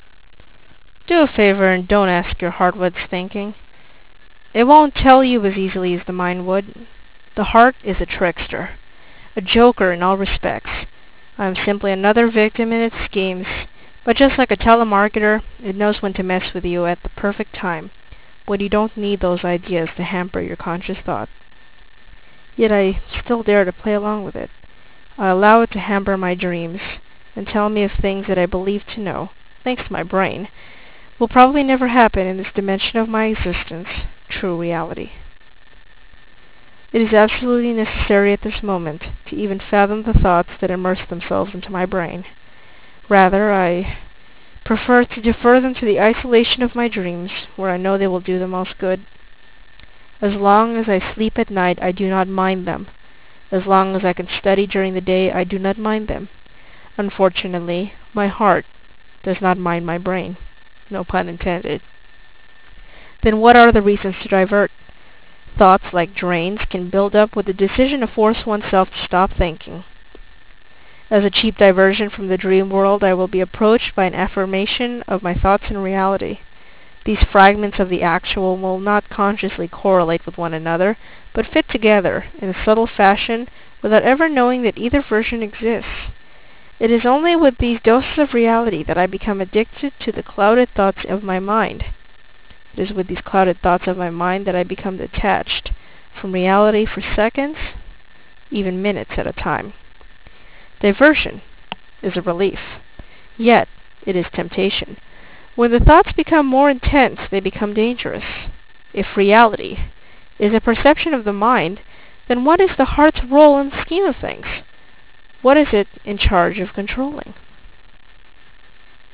Any distortion does not reflect the actual CD track. (It is only a consequence of compression.)